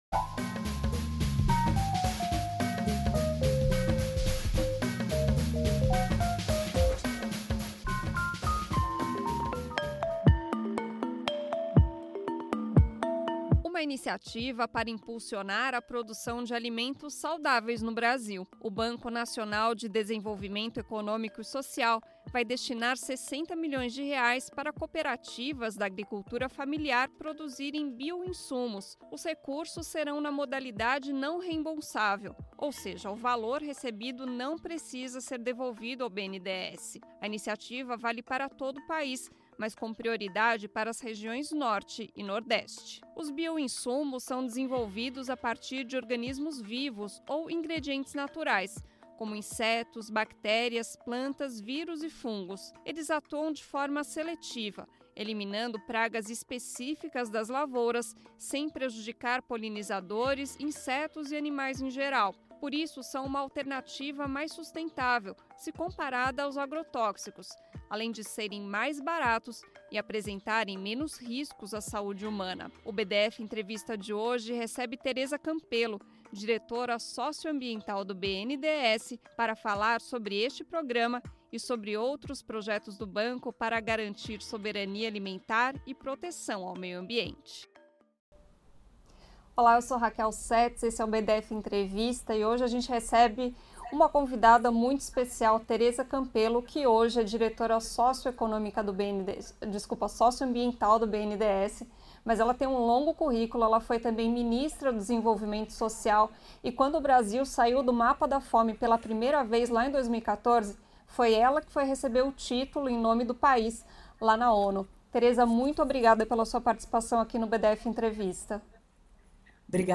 A diretora socioambiental do Banco Nacional de Desenvolvimento Econômico e Social (BNDES), Tereza Campello, defende que o Brasil precisa investir em bioinsumos e agricultura familiar para garantir a soberania alimentar e enfrentar a emergência climática. Em entrevista ao BdF Entrevista , da Rádio Brasil de Fato, ela critica o modelo do agronegócio baseado na exportação de commodities e uso intensivo de venenos agrícolas .